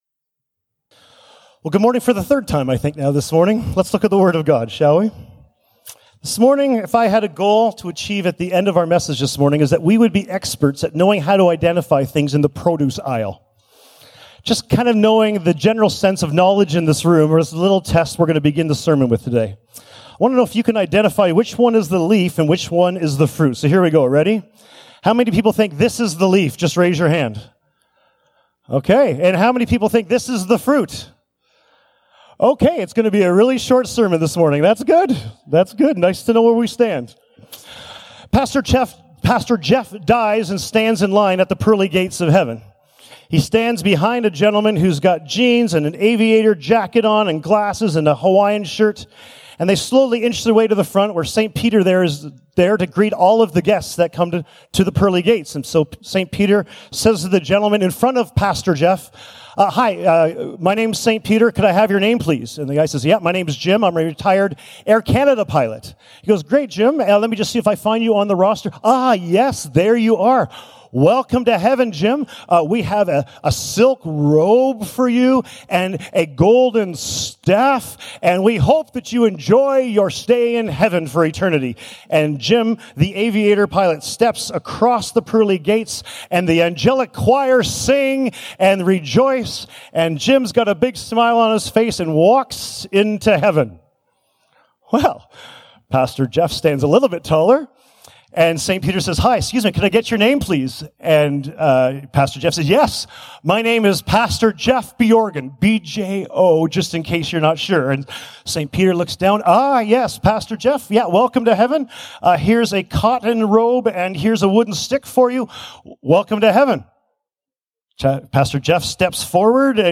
Sermons | Emmanuel Church